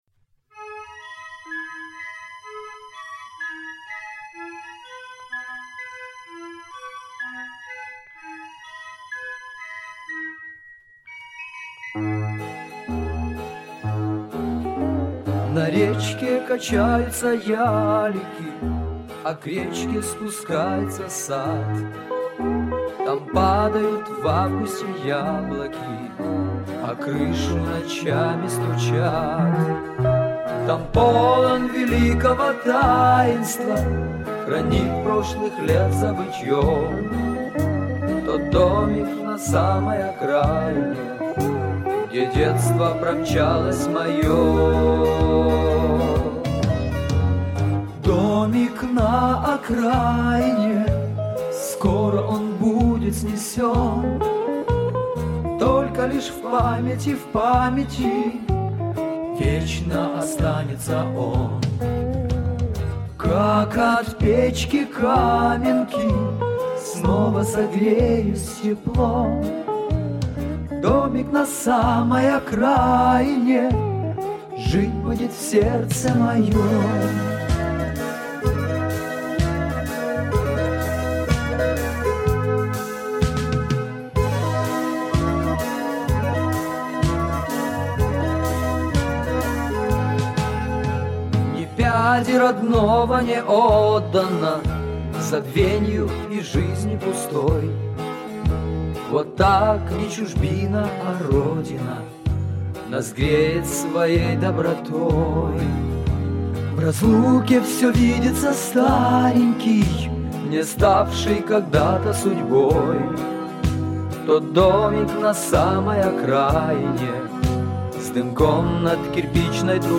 Русский Рок
Задавили шумоподавителем первый трек.